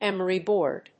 アクセントémery bòard